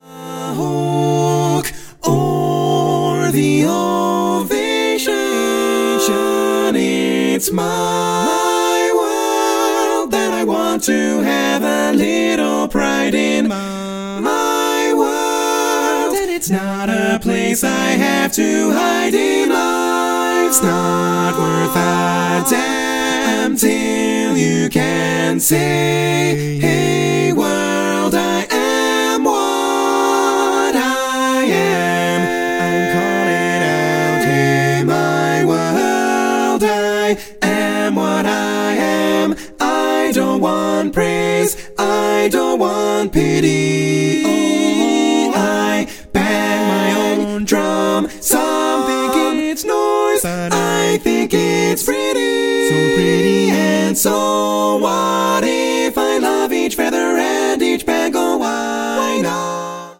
Full mix only
Category: Female